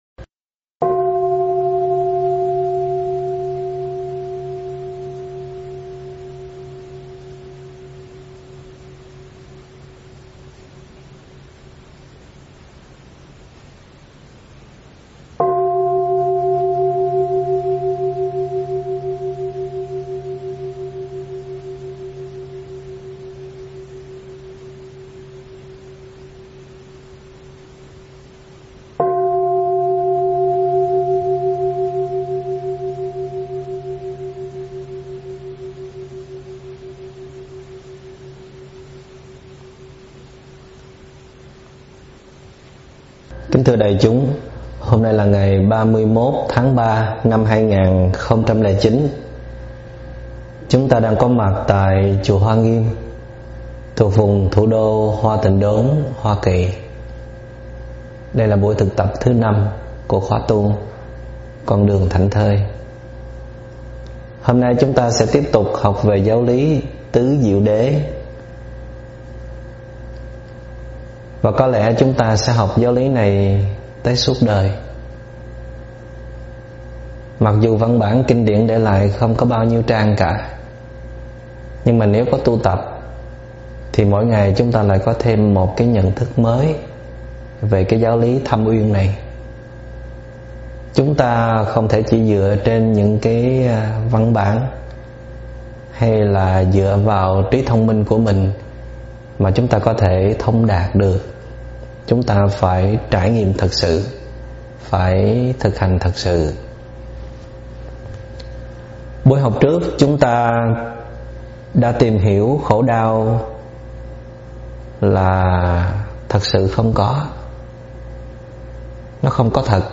Nghe mp3 thuyết pháp Biến Khổ Đau Thành Hạnh Phúc Chân Thực do ĐĐ. Thích Minh Niệm giảng tại chùa Hoa Nghiêm, Hoa Kỳ ngày 31 tháng 3 năm 2009